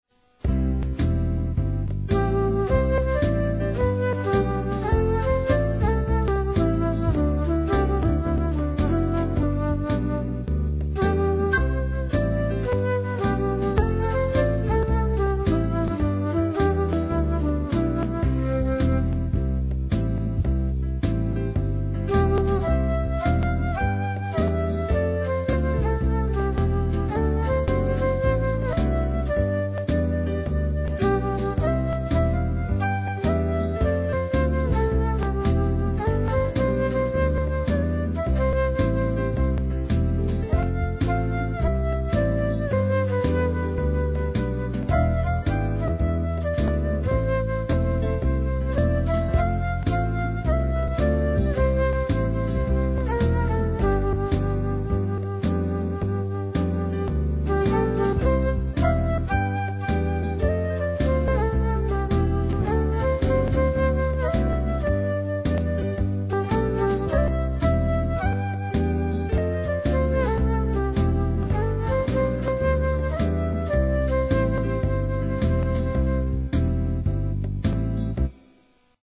Jazz/Swing mp3